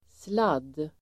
Uttal: [slad:]